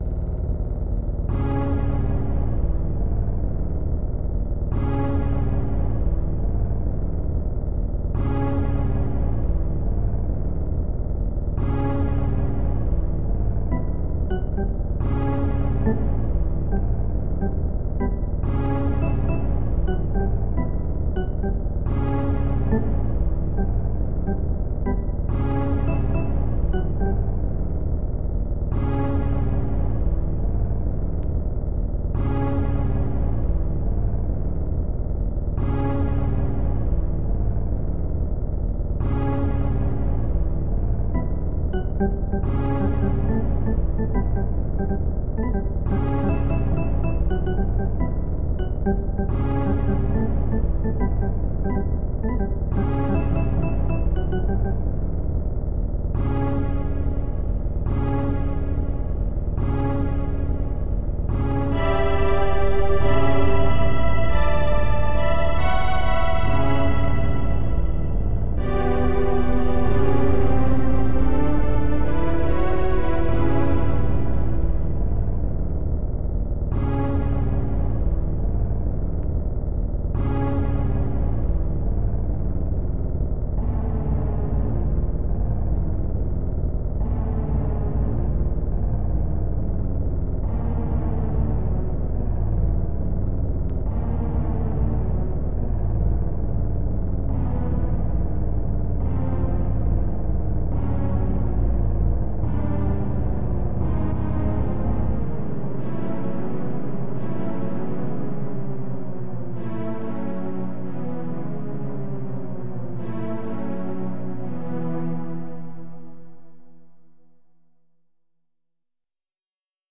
★太鼓を１６分刻みにしてるからＭＩＤＩで聴くとすっごくうるせー